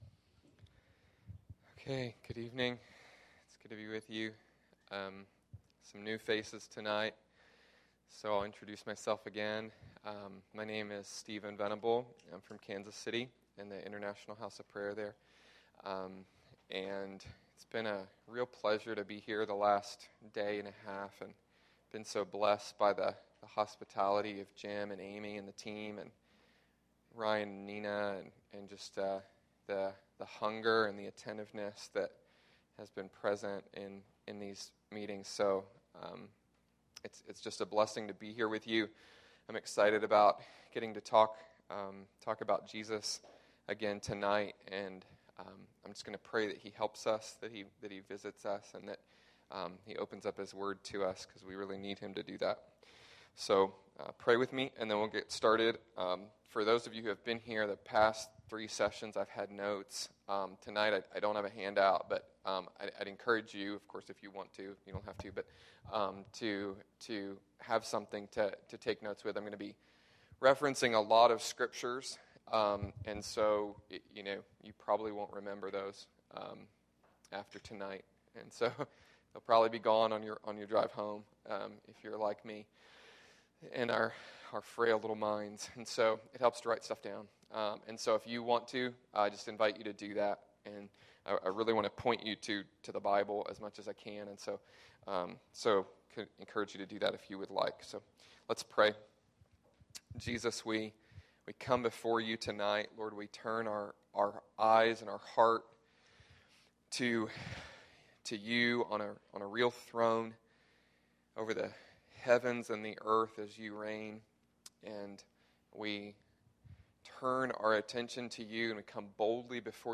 This is the fourth session of the Passion for Jesus series from the event held on this weekend.